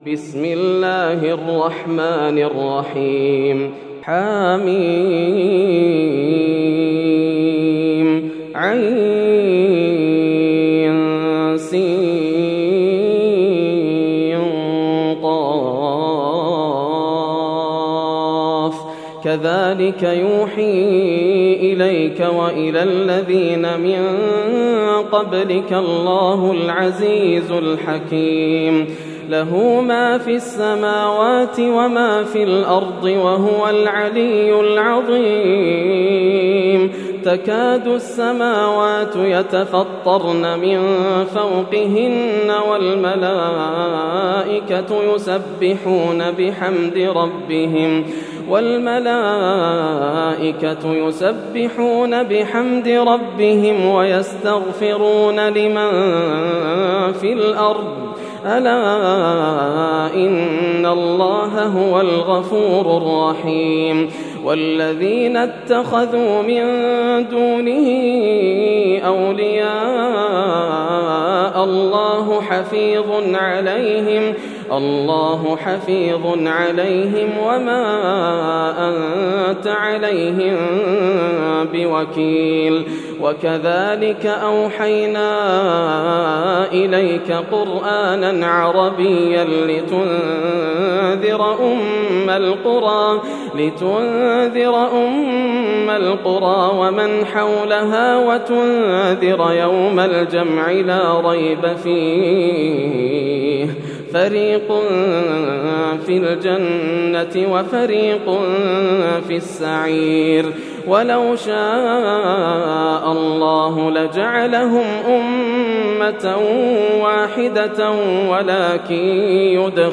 سورة الشورى > السور المكتملة > رمضان 1431هـ > التراويح - تلاوات ياسر الدوسري